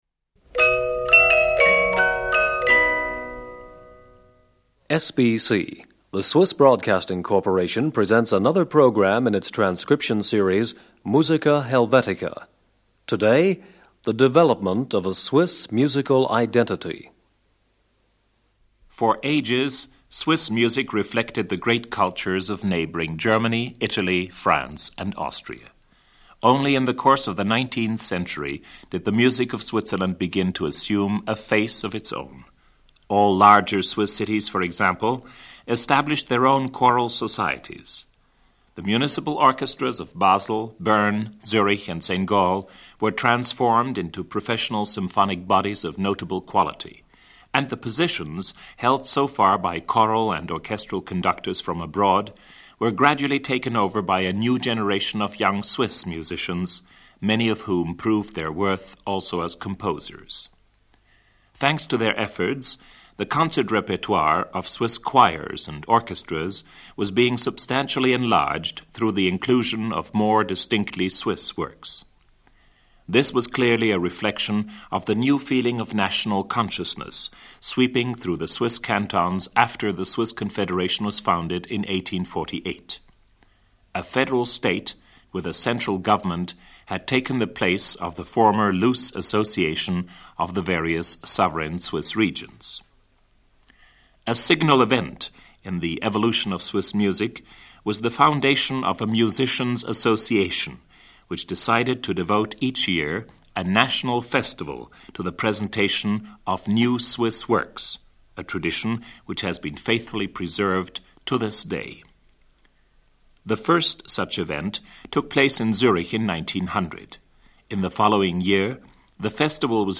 Emile Jacques-Dalcroze: Six Rythmes de Danse (excerpt for String Quartet) 2.
Hermann Suter: Il Vivace from Sextet in C major for String Instruments 6.